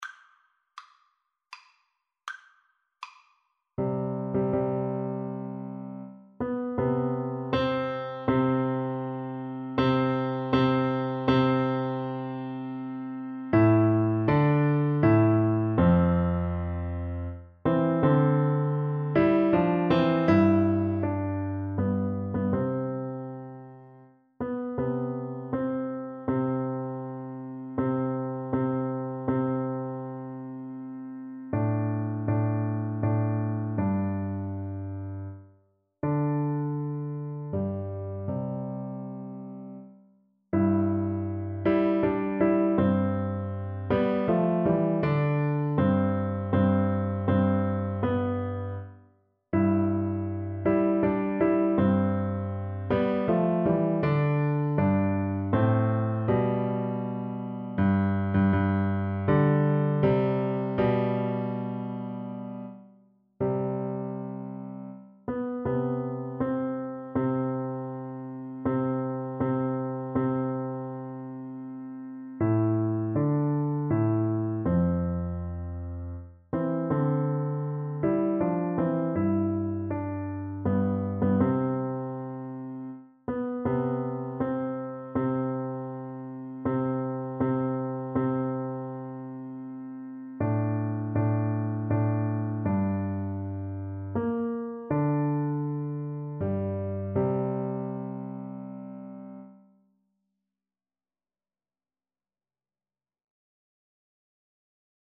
Andante
3/4 (View more 3/4 Music)
Classical (View more Classical Piano Duet Music)